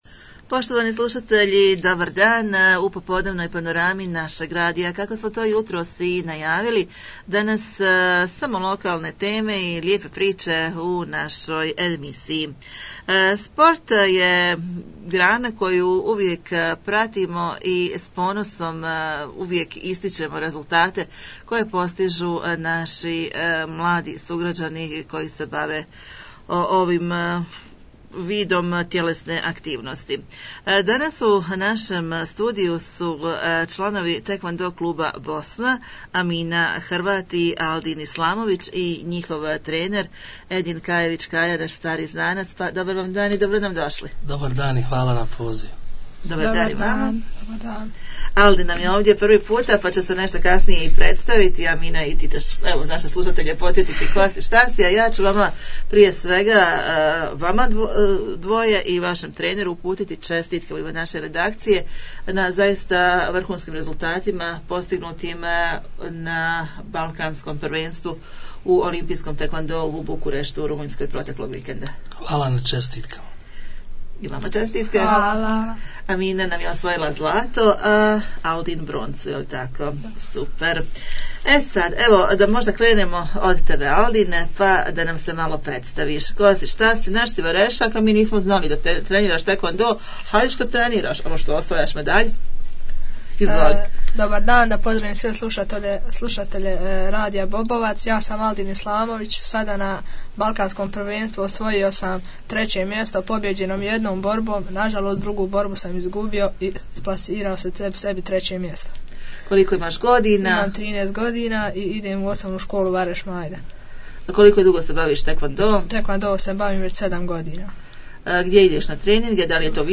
Razgovor s balkanskim prvacima u teakwondou